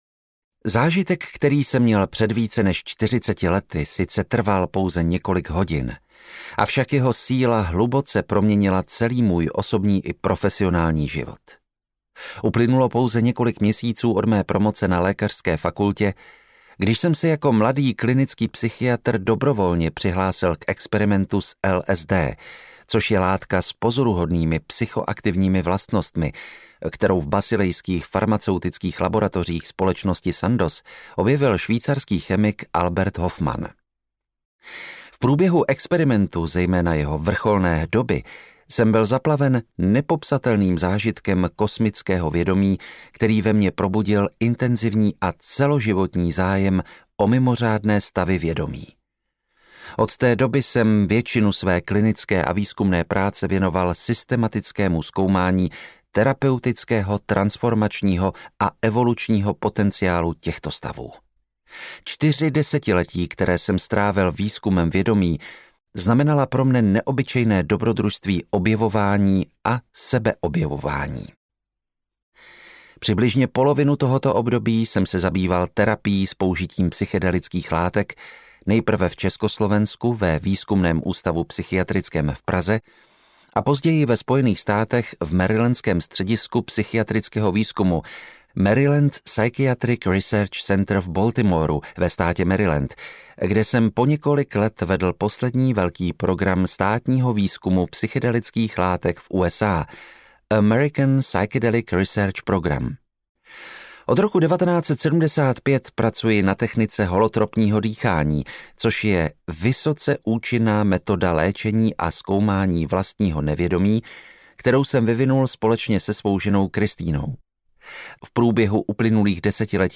Psychologie budoucnosti audiokniha
Ukázka z knihy